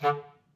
Clarinet
DCClar_stac_D2_v3_rr2_sum.wav